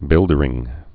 (bĭldər-ĭng)